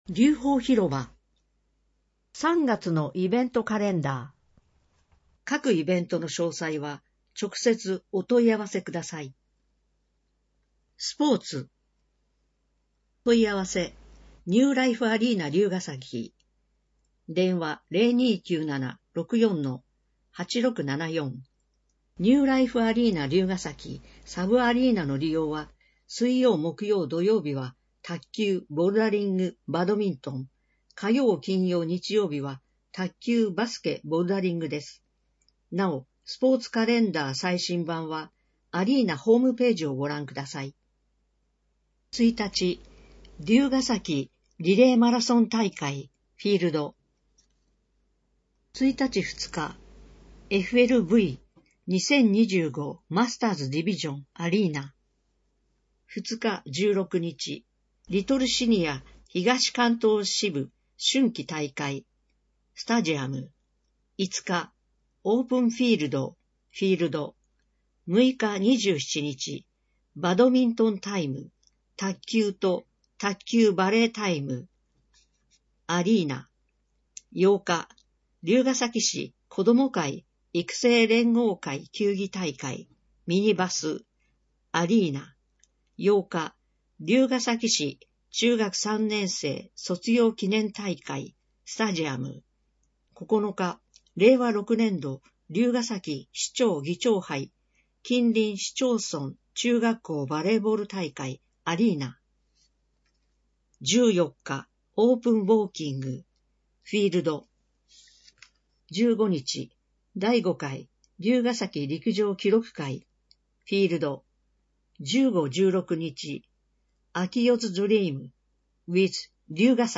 『りゅうほー』の音訳CD・点訳版を配布しています